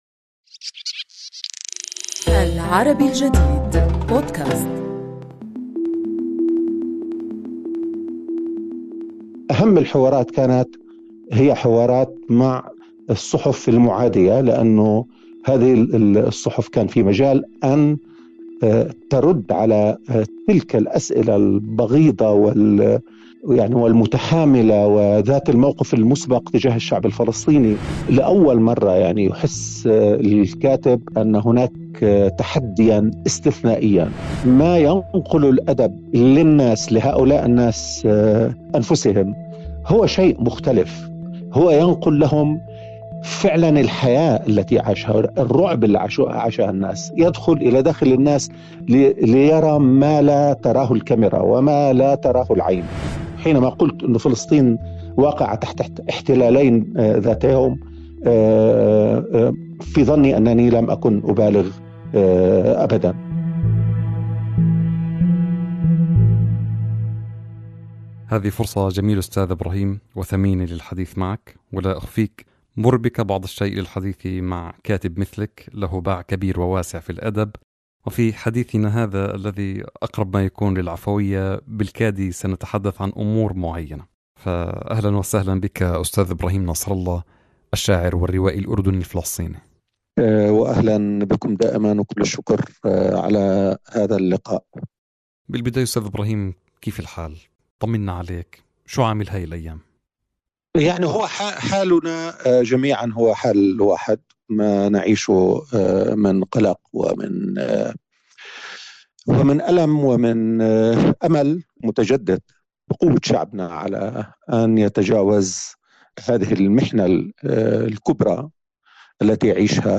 نستضيف في هذه الحلقة من بودكاست "فيه ما فيه" الشّاعر والرّوائي الأردني الفلسطيني إبراهيم نصر الله، صاحب مشروع "الملهاة الفلسطينية" الرّوائي، الذي بلغ حتى اللحظة 15 عملاً روائياً يتتبّع فيها خطّاً زمنياً للتاريخ الفلسطيني المعاصر، كلّ رواية فيها مستقلّة عن الأخرى.